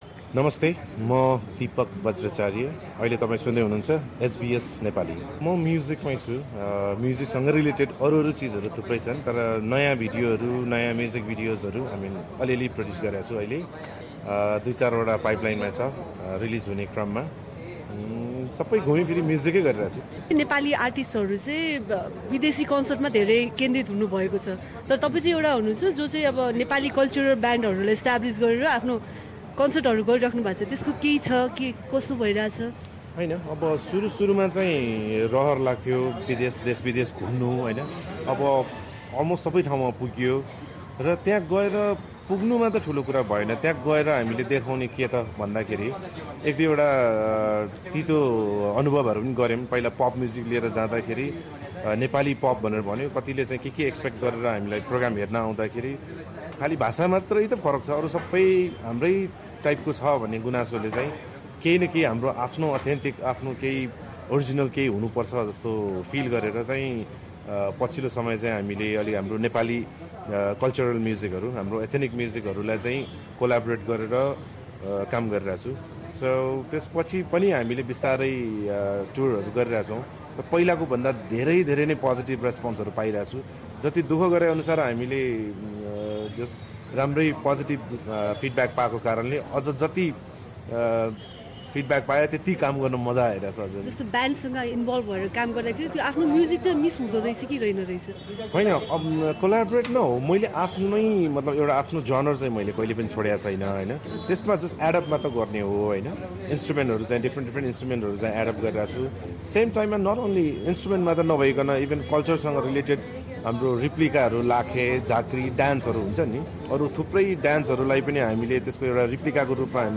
आफ्ना सांगीतिक यात्राबारे उनले एसबिएस नेपालीसँग गरेको कुराकानी